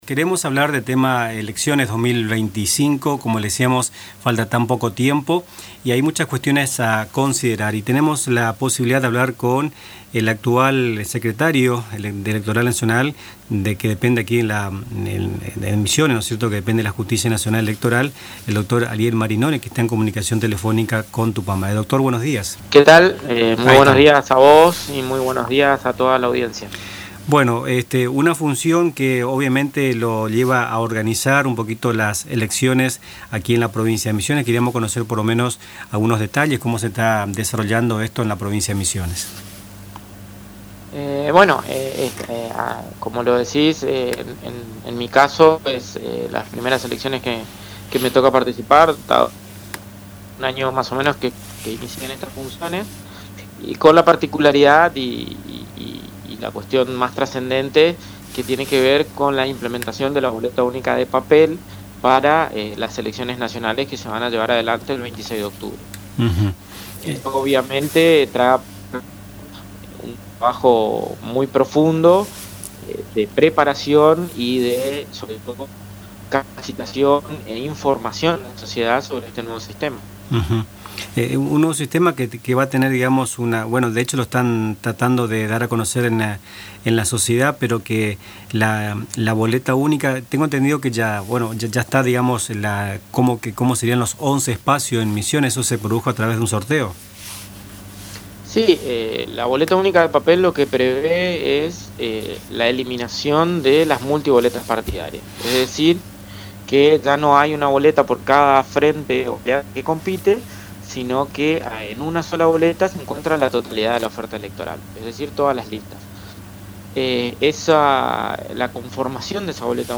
En diálogo con Nuestras Mañanas, el secretario electoral nacional en Misiones, Dr. Ariel Marinoni, brindó precisiones sobre la organización de las elecciones nacionales del próximo 26 de octubre, en las que la provincia aplicará por primera vez el sistema de Boleta Única de Papel.